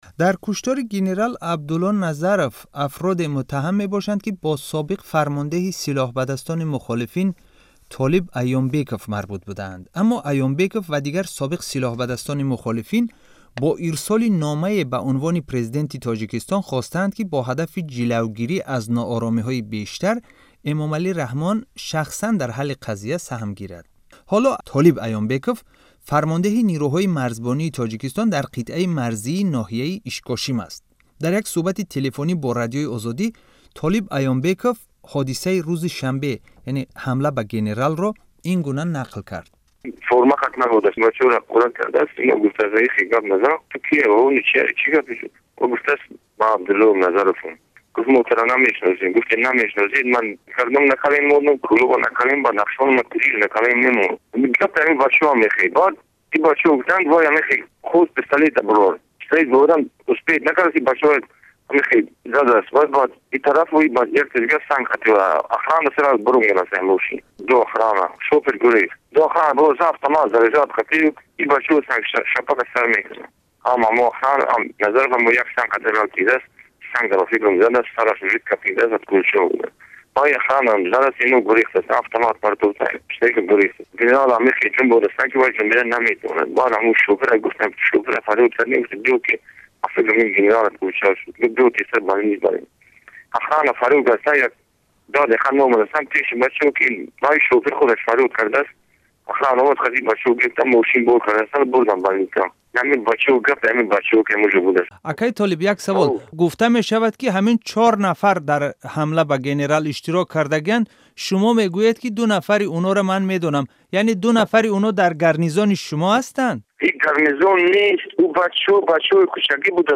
Мусоҳибаи Толиб Аёмбеков бо радиои Озодӣ